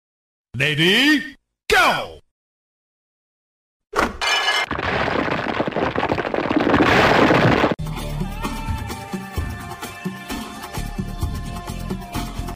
Cat Satisfying Video clip with sound effects free download